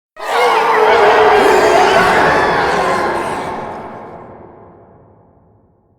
Hordescream.wav